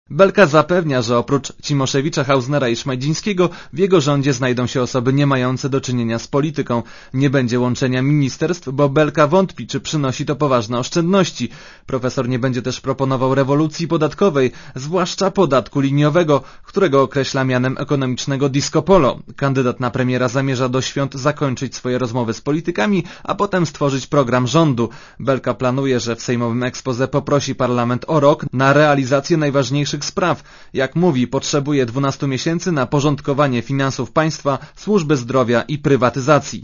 Źródło: RadioZet Relacja reportera Radia Zet Oceń jakość naszego artykułu: Twoja opinia pozwala nam tworzyć lepsze treści.